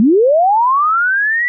Free UI/UX sound effect: Power Up.
032_power_up.mp3